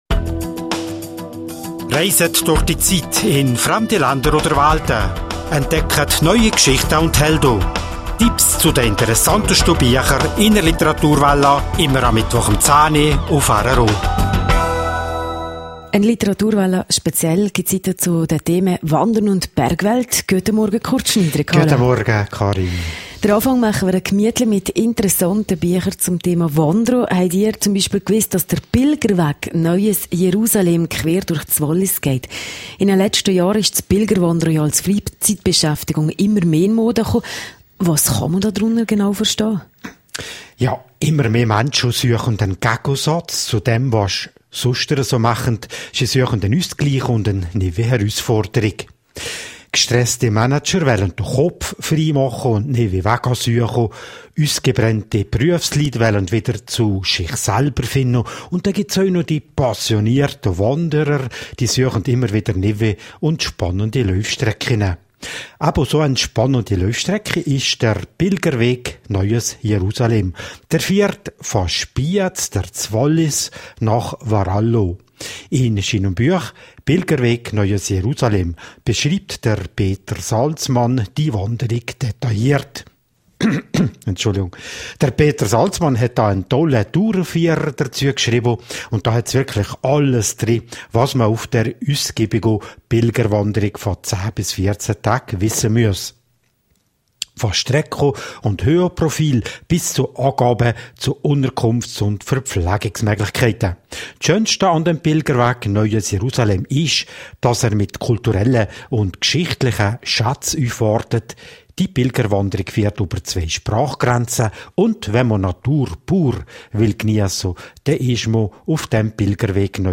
14.09.2011. In der Literaturwelle erfahren Sie Interessantes zu originellen Wanderrouten im Wallis. Sie hören zudem eine Geschichte, die von einem irrsinnigen Bungee-Sprung von der Ganterbrücke erzählt, der ein junges Leben in ganz andere Bahnen gelenkt hat. Und Sie philosophieren schliesslich zusammen mit dem Extrem-Bergsteiger Oswald Oelz über die Frage, ob es das Risiko braucht, um unserem Leben einen Sinn zu geben.